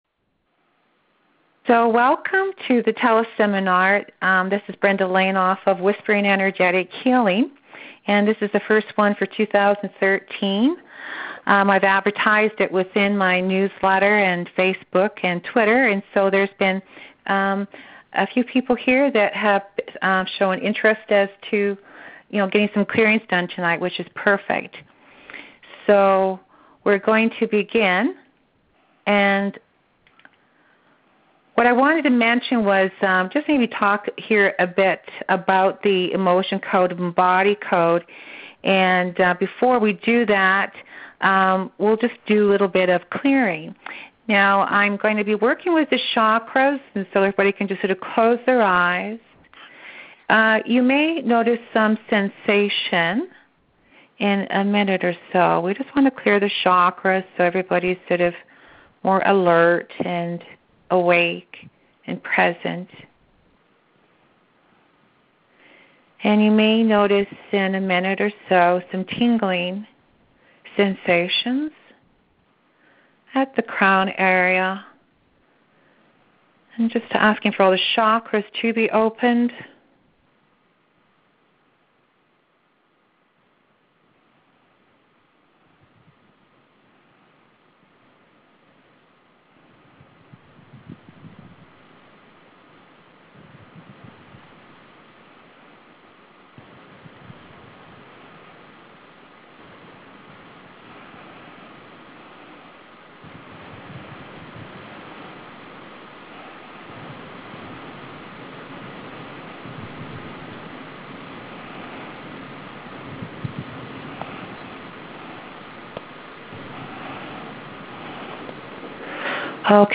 Teleconference